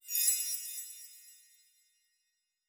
Magic Chimes 02.wav